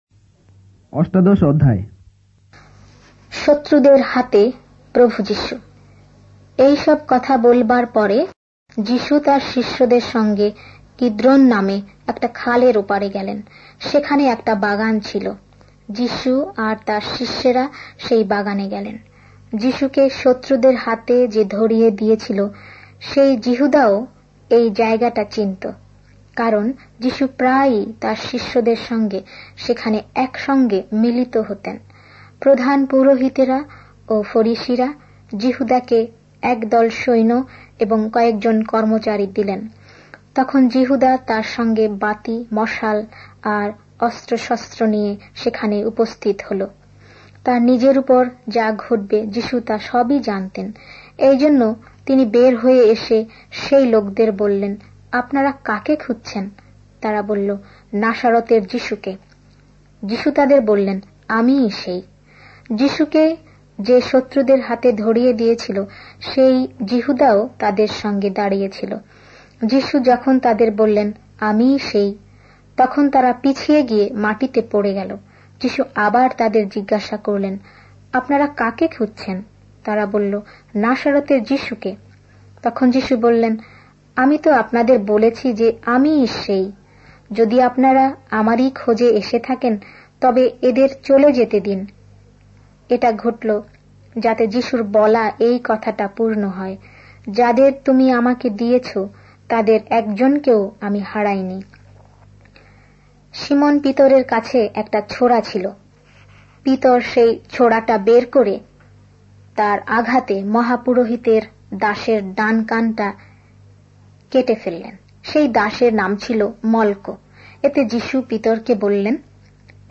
Bengali Audio Bible - John 1 in Bnv bible version